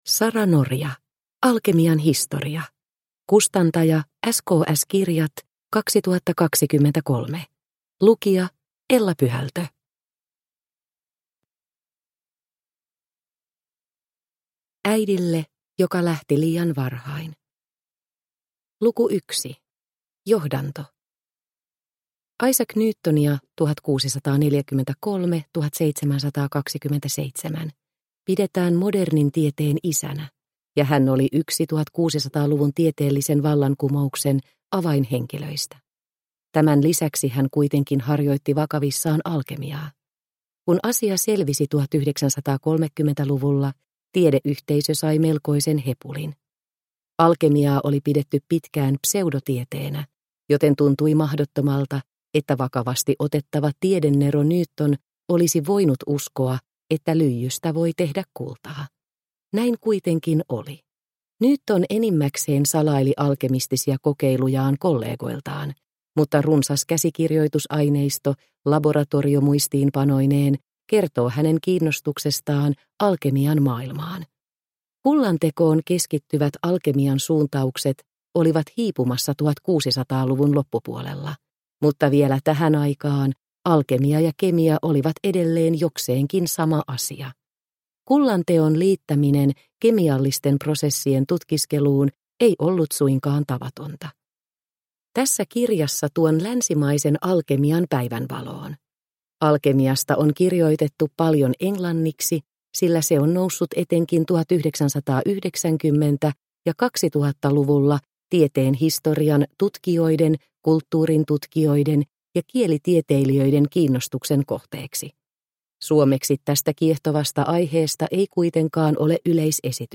Alkemian historia – Ljudbok – Laddas ner